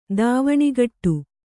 ♪ dāvaṇigaṭṭu